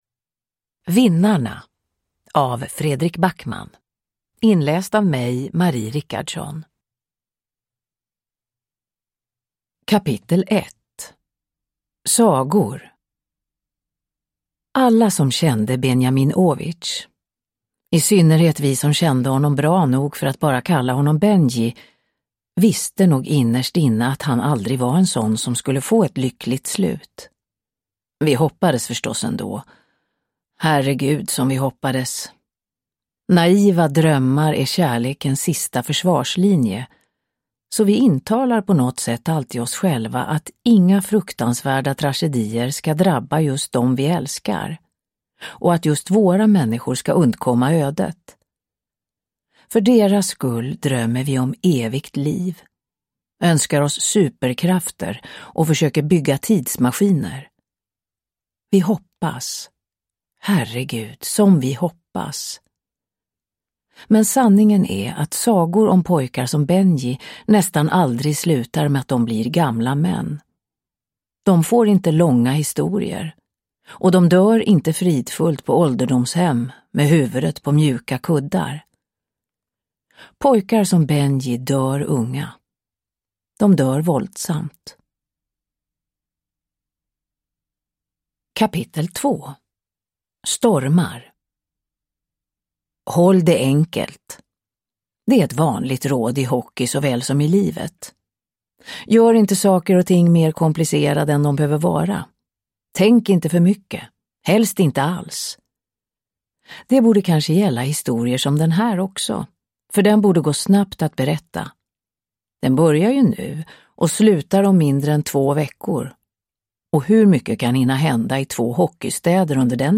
Vinnarna – Ljudbok
Uppläsare: Marie Richardson